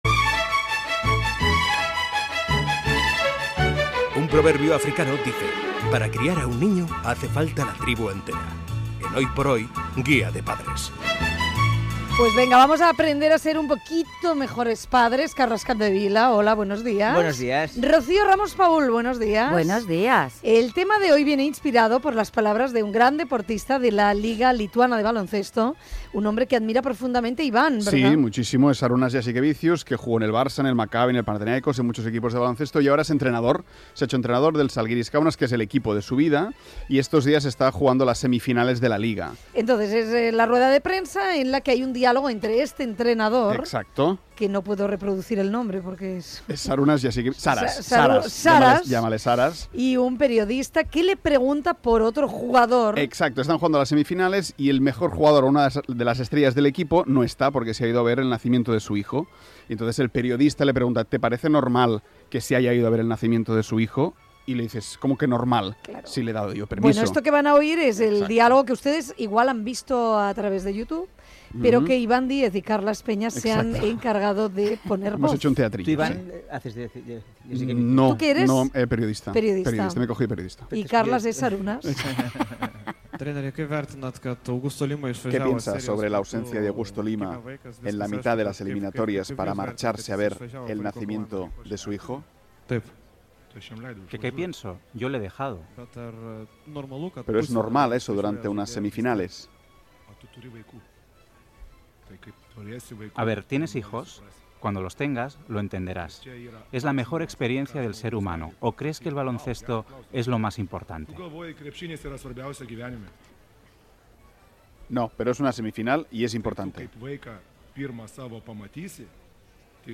Exemples d'esportistes, trucades de l'audiència i comentaris dels especialistes
Info-entreteniment